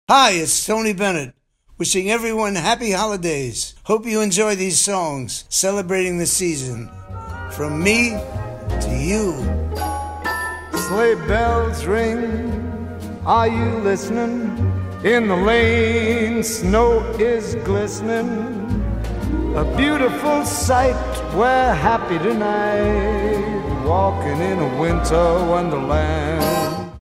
Christmas classics